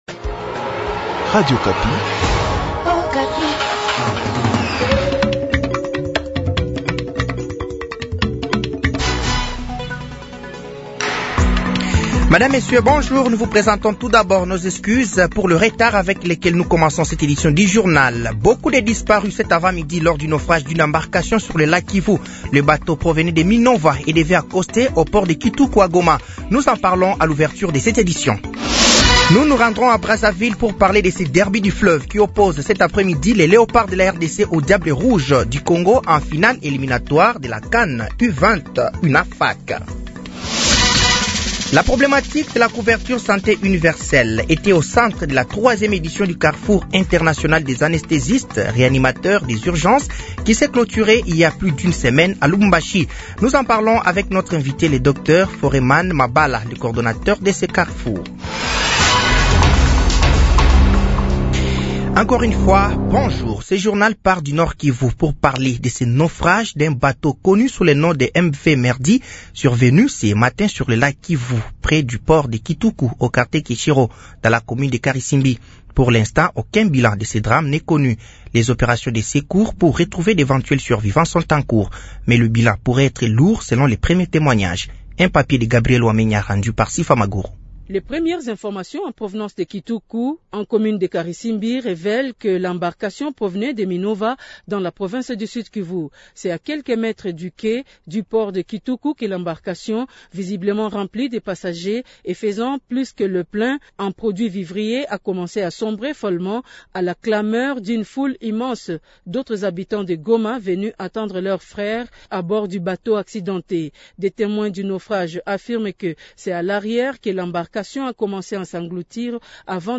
Journal français de 12h de ce jeudi 03 octobre 2024